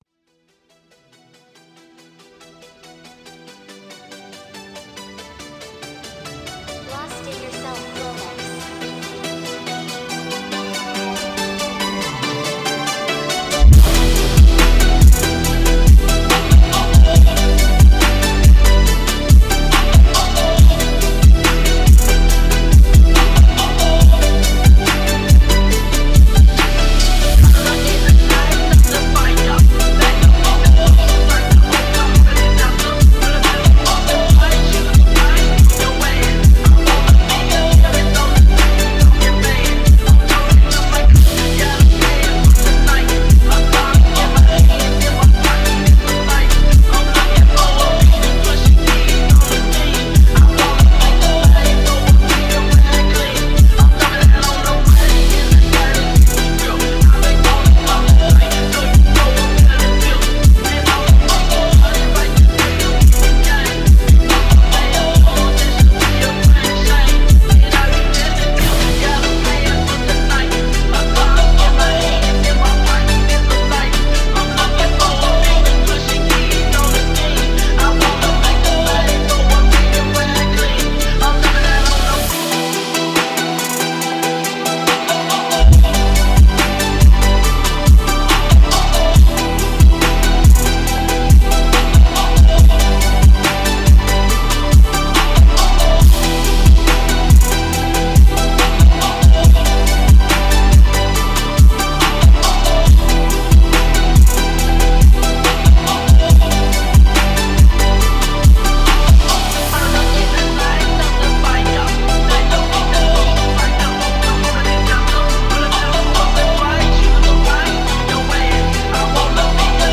Фонк музыка
Phonk музыка